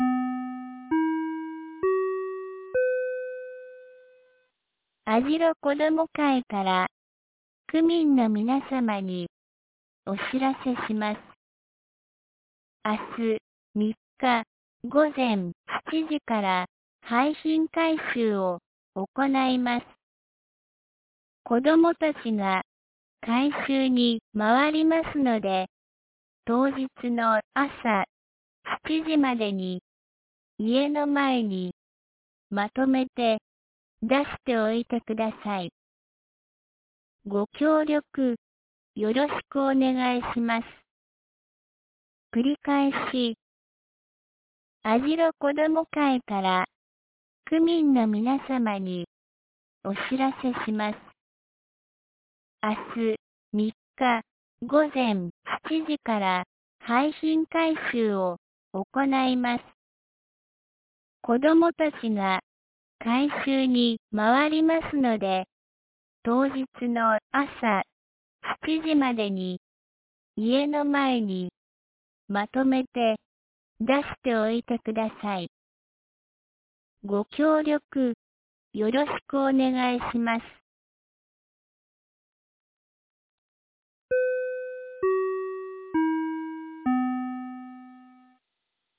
2019年11月02日 12時27分に、由良町から網代地区へ放送がありました。